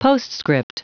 Prononciation du mot postscript en anglais (fichier audio)
Prononciation du mot : postscript